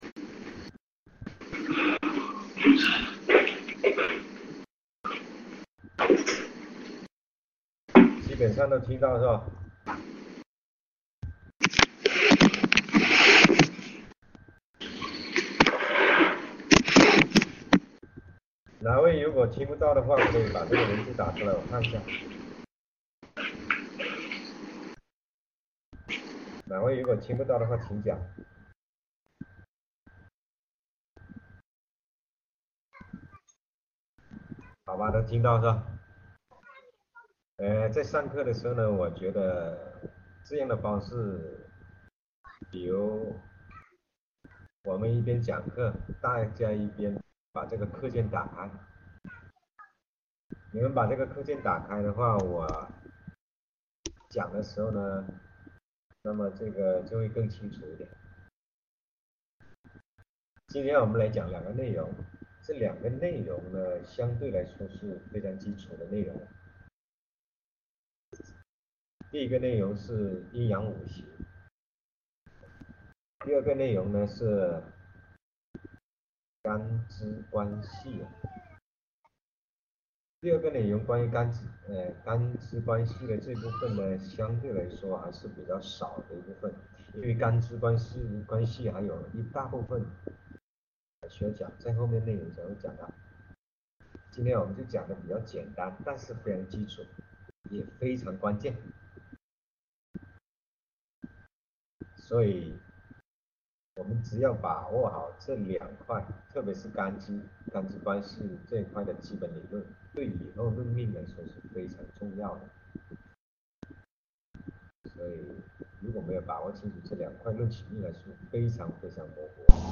音频是普通话的也可以听，偶有断续，但就是没那么直观。